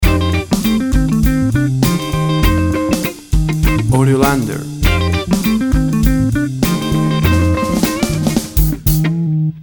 Tempo (BPM) 120